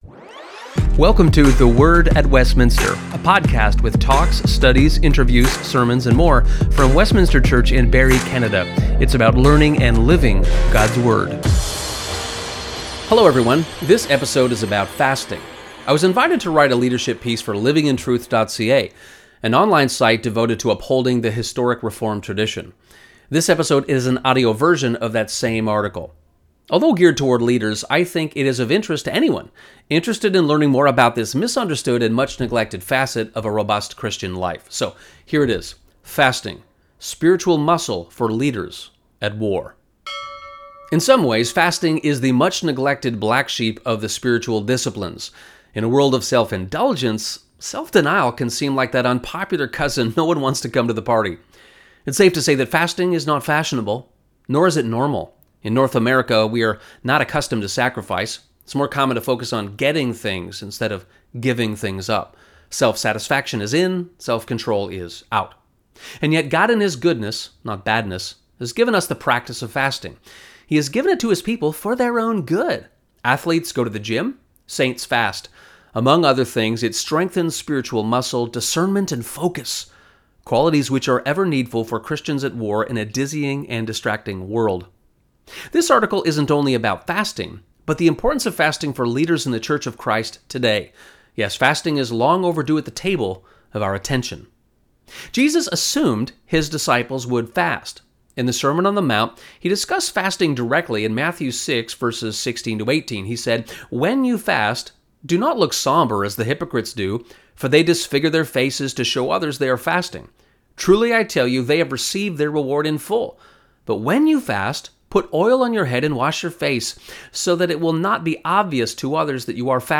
This episode is an audio version of that same article.